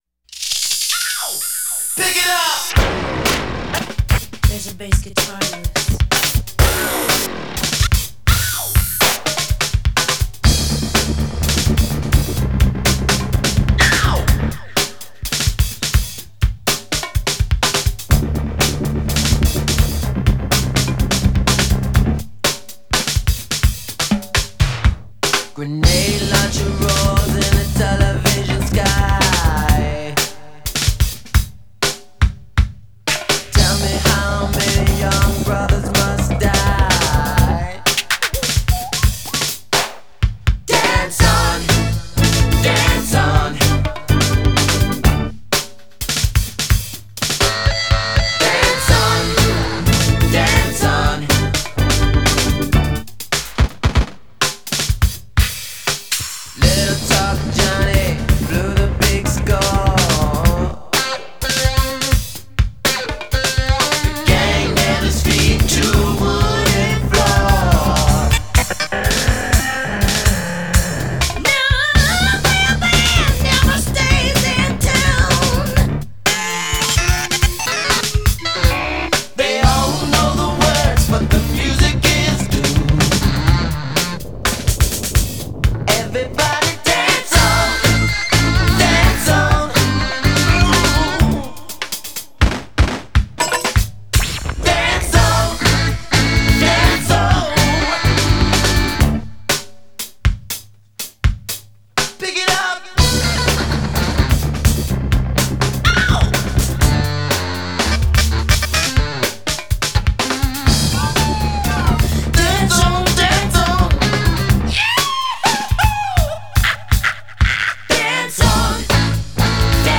At Paisley Park Studios